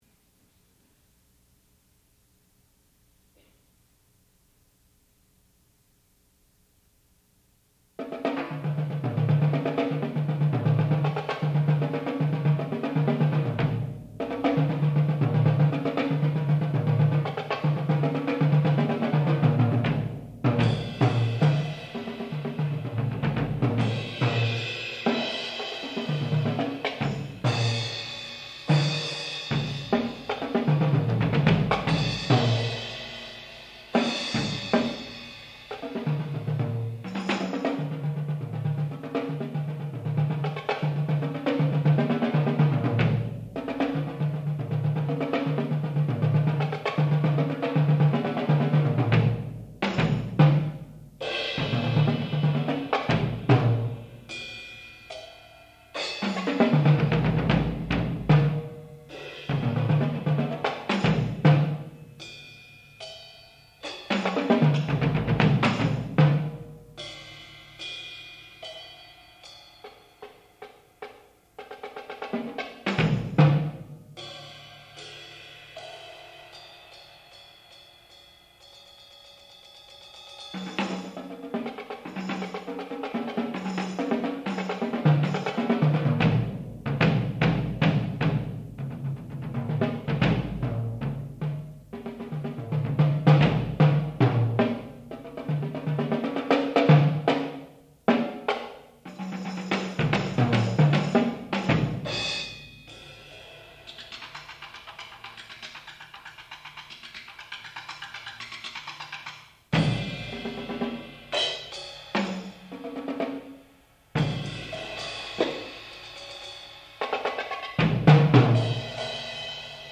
English Suite, Multiple Percussion Solo
sound recording-musical
classical music
Student Recital
percussion